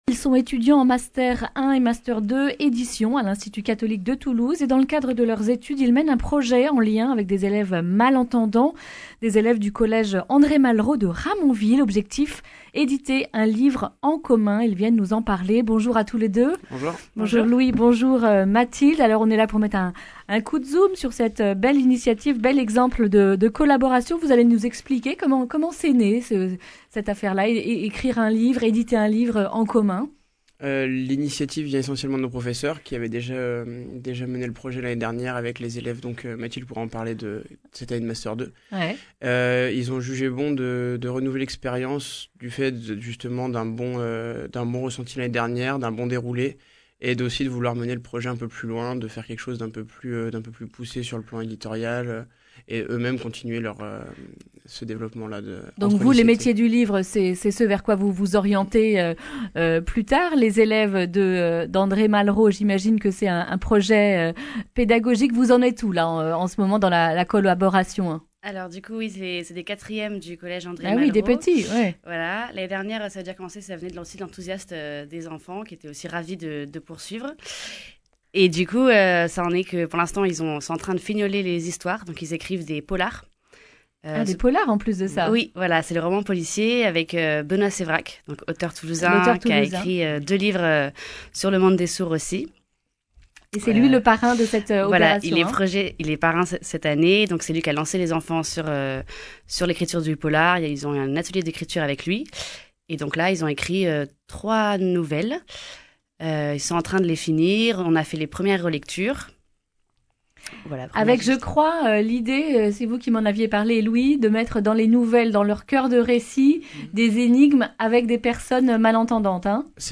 lundi 10 février 2020 Le grand entretien Durée 10 min